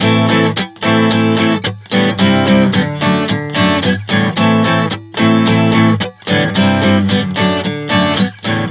Prog 110 Am-G-D.wav